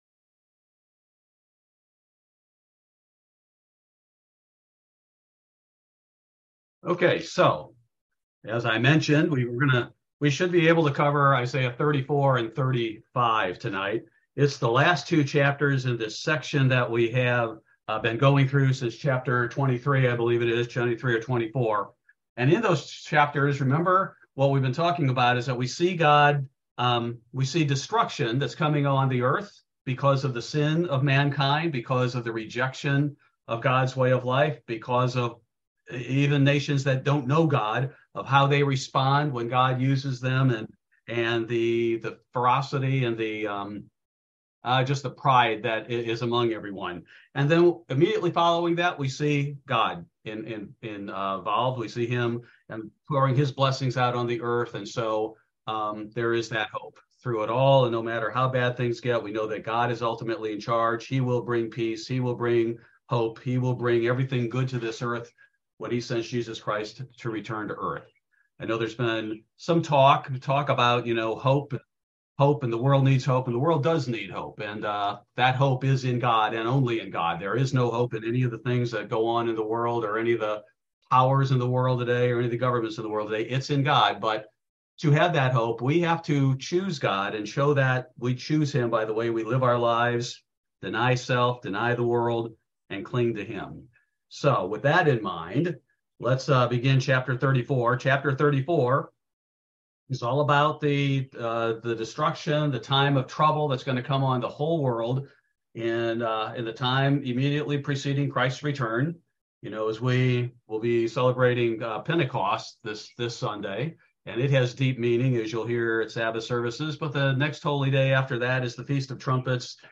This is a verse by verse Bible Study that focuses primarily on Isaiah 34-35: Lines of Confusion, Stones of Emptiness, and Hope